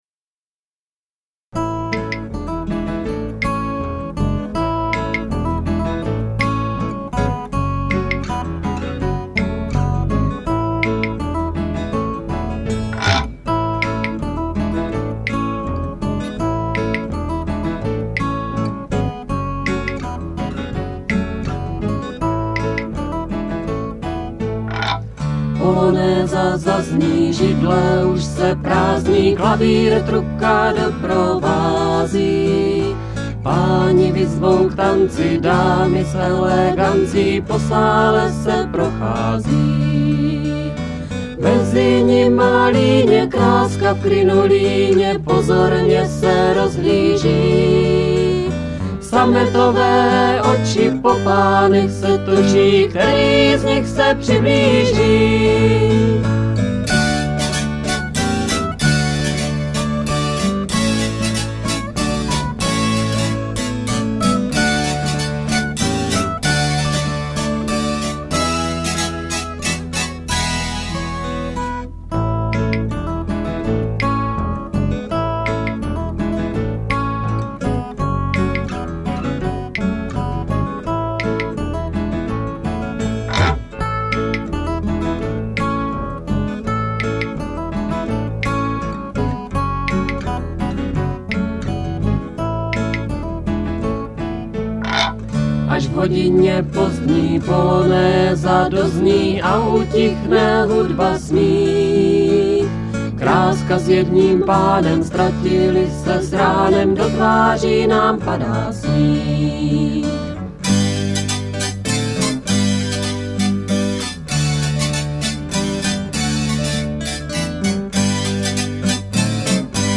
DEMO-DOMA 1996, MP3